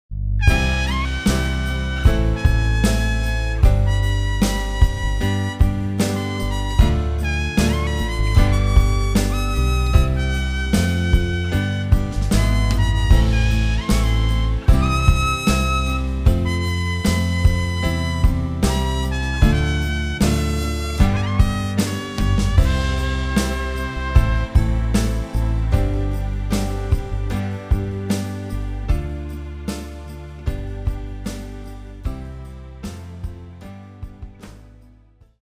Je kan deze verhalen zelf lezen of voorlezen.